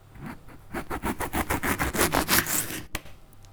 • cutting a lemon.wav
cutting_a_lemon_u9D_jcV.wav